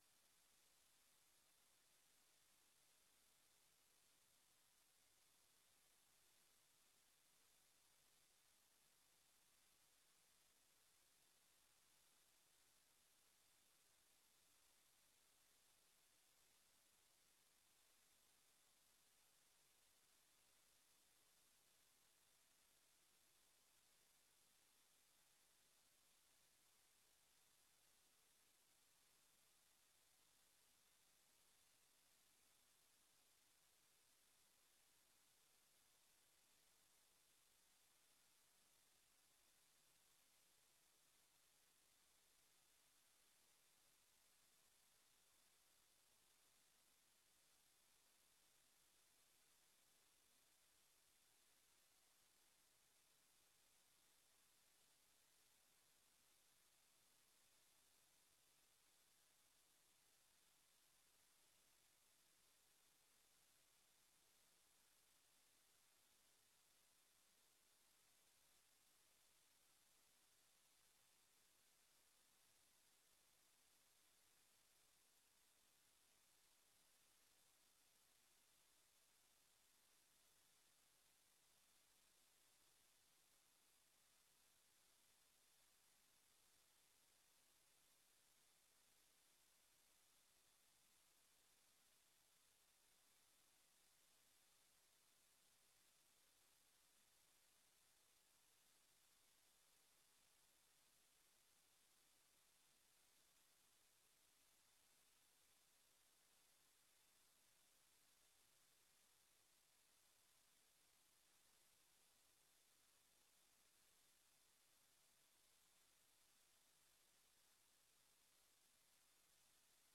Raadsvergadering 16 oktober 2025 20:00:00, Gemeente Oude IJsselstreek